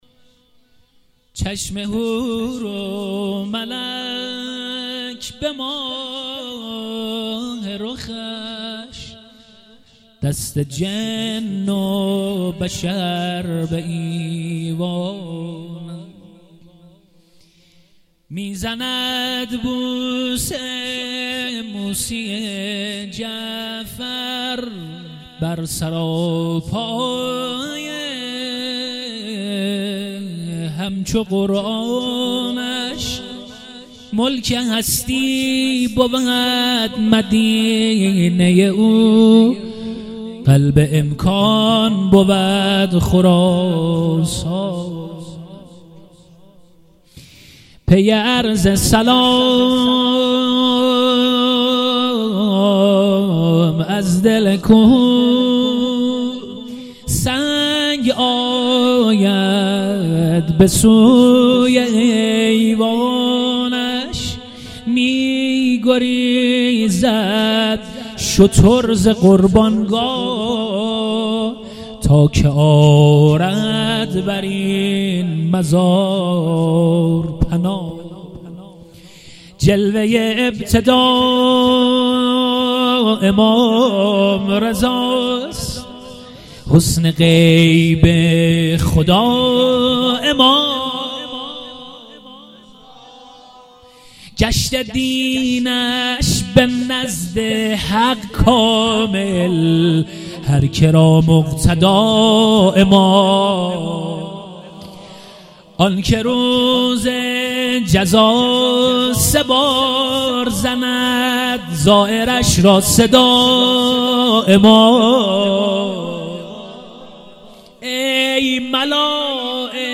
جلسات هفتگی
مولودی